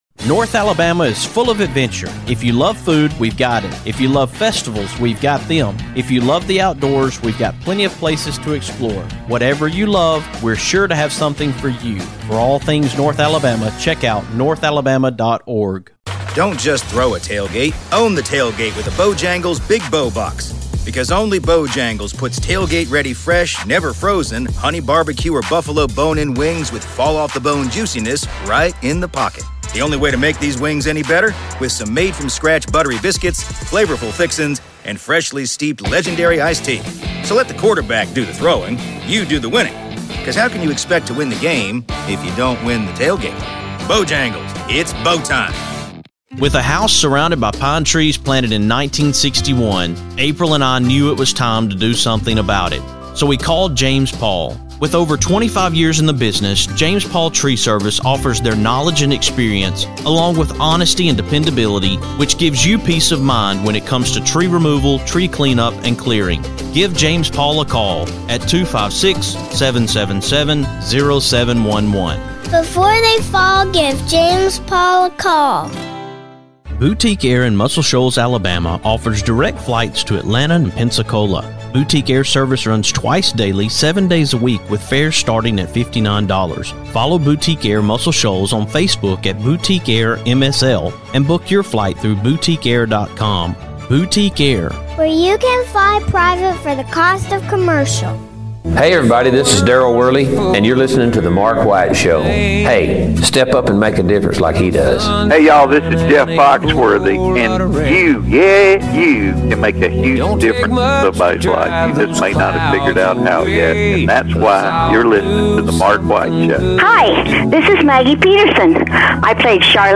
On this edition of TMWS, I have country music artist Doug Stone!
It was a pleasure to be able to have a conversation with him today.